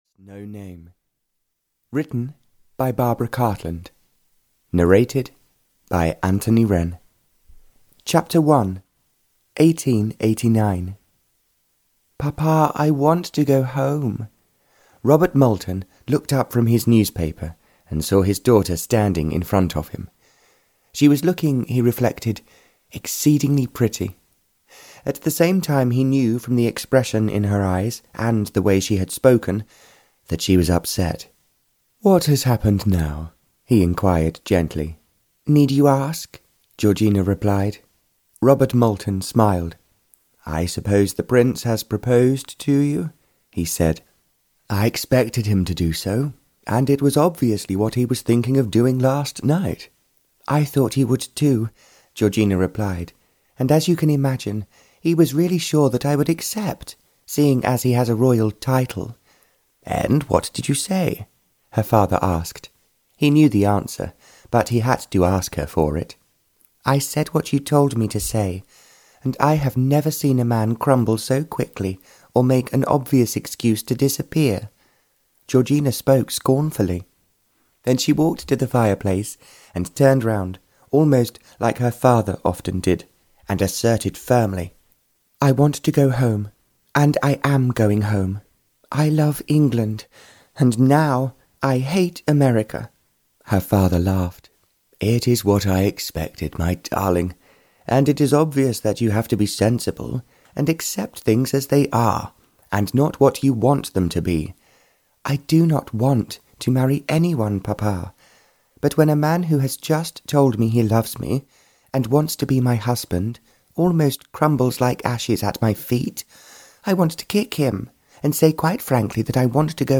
Audio knihaLove Has No Name (Barbara Cartland's Pink Collection 156) (EN)
Ukázka z knihy